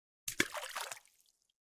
Among Us Vote Out Lava Drop Sound Effect Free Download